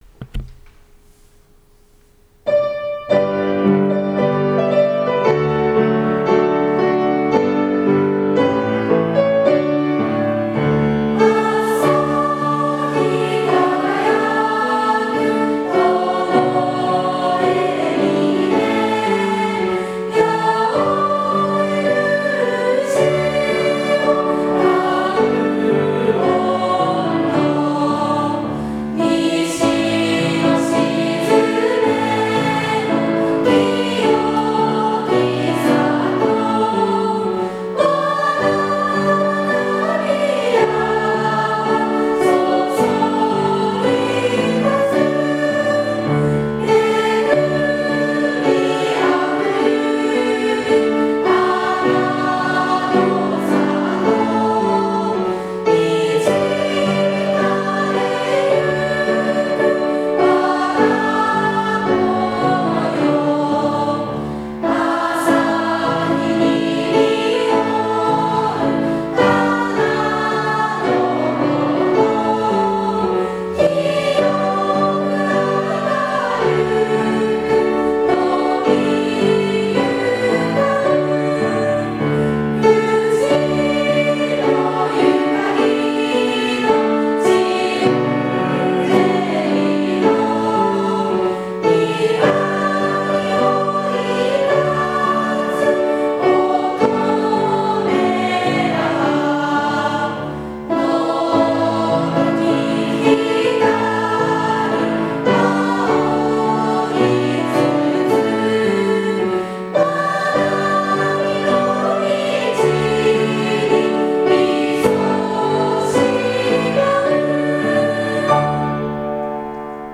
宗教研究部（雅楽部）と吹奏楽部で合唱して
気持ちを込めて歌ったことと思います。
２つの部活動による女子校時代の校歌をご紹介します。